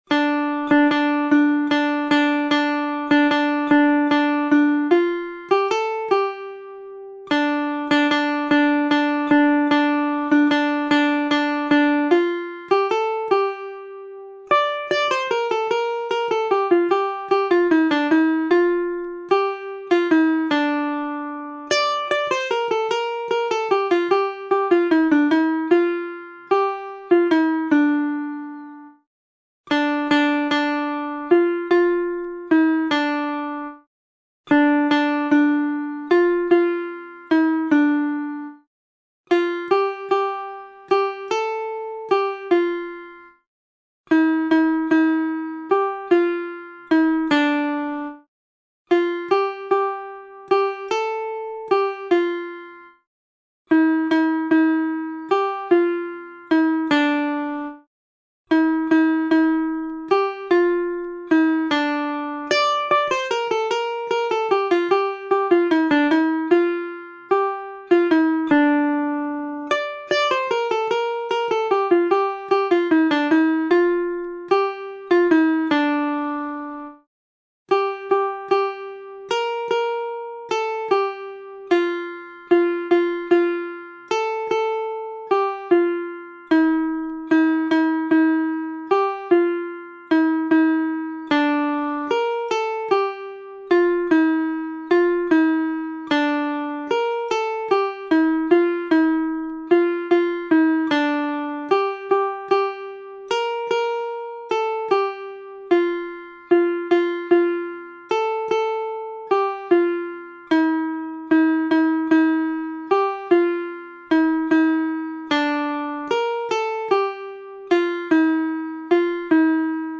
از گام می بمل مینور به گام ر مینور انتقال داده شد